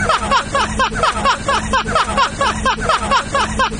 Tales laughing Download
tales-rindo.mp3